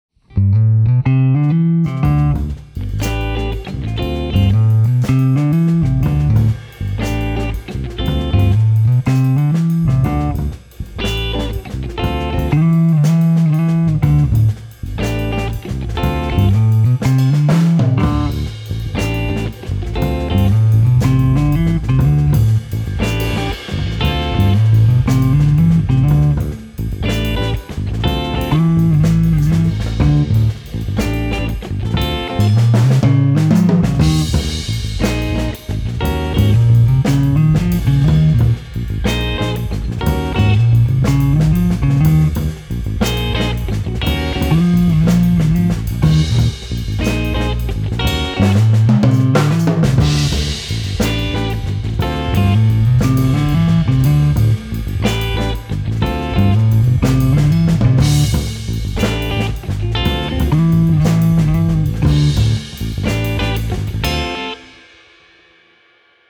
– Slo What (Slow Funk, les guitare se faisant les réponses) [mp3 V1 /